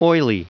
Prononciation du mot oily en anglais (fichier audio)
Prononciation du mot : oily